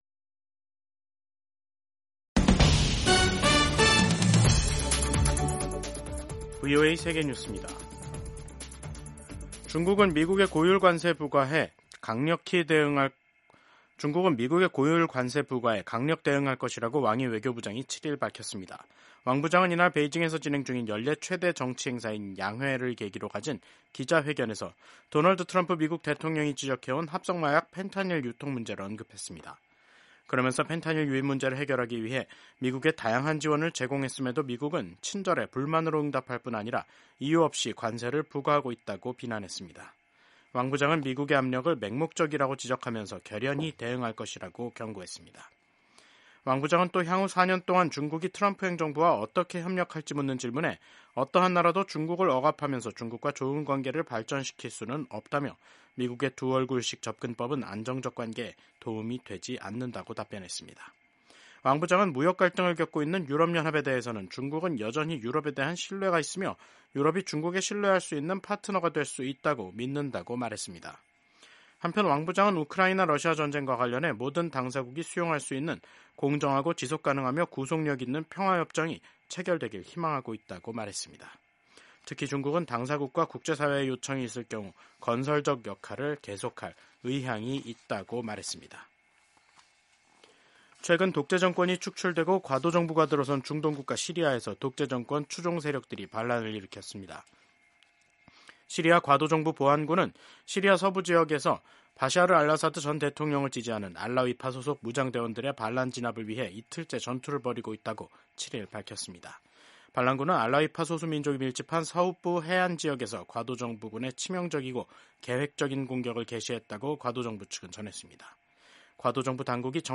생방송 여기는 워싱턴입니다 2025/3/7 저녁